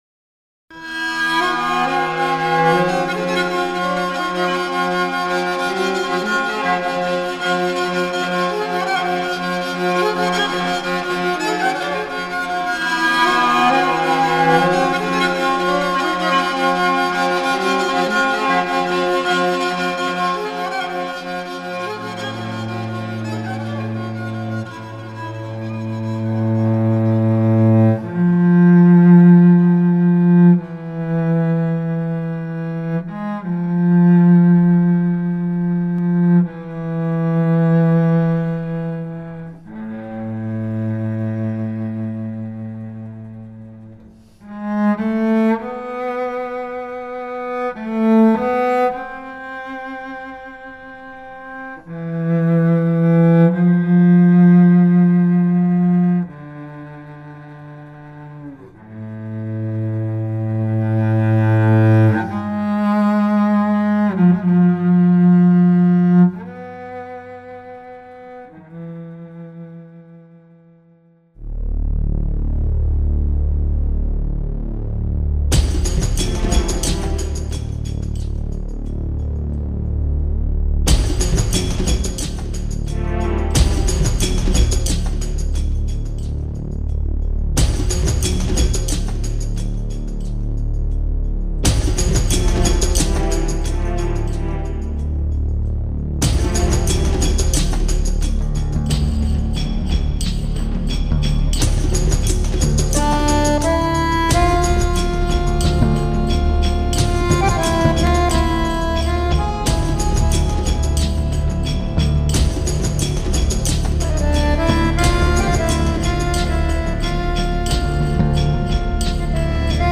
voce, percussioni, tastiere, lira macedone
voce, duduk, saz, pianoforte, chitarra
Violino
violino, viola
Violoncello
chitarra acustica ed elettrica
Fisarmonica
Sax alto
Tuba, trombone